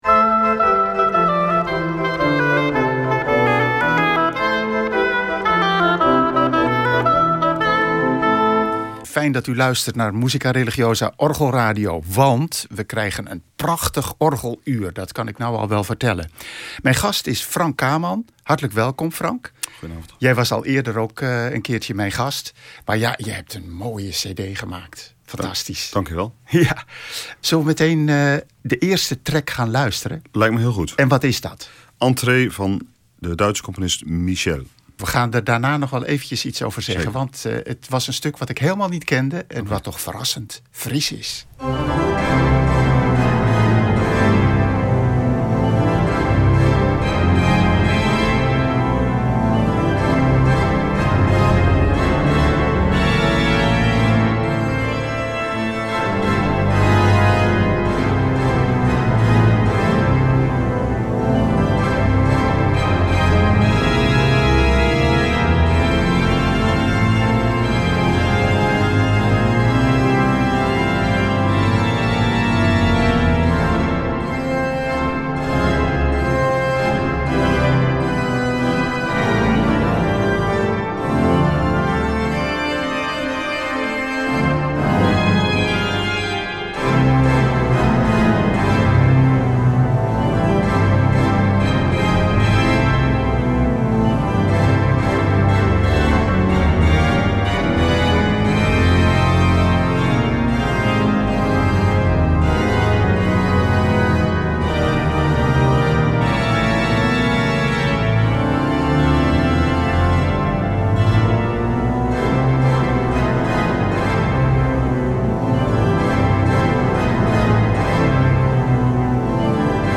Het stuk is in Jazz/Big Band stijl geschreven en afkomstig uit ‘Petit Suite in Blue’.Nadat verscheidene werken in afwisselende stijlen hoorbaar zijn, wordt afgesloten met ‘U zij de Glorie’.